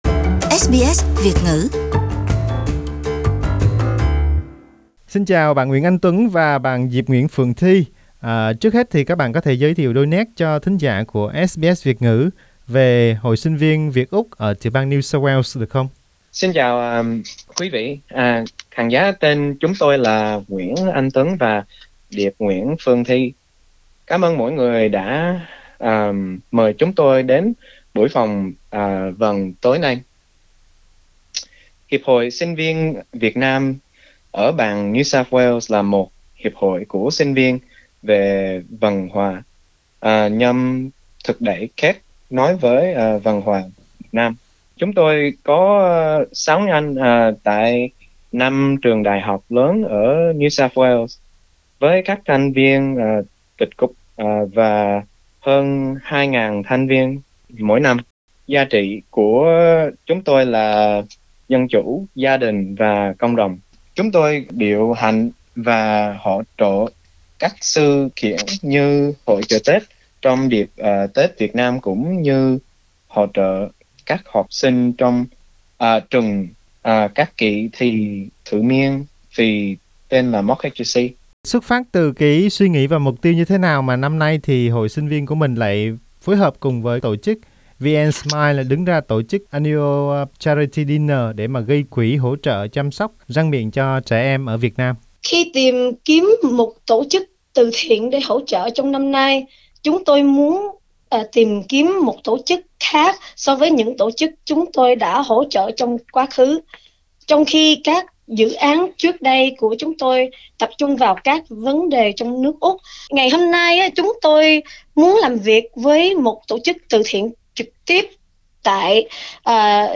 SBS Việt ngữ phỏng vấn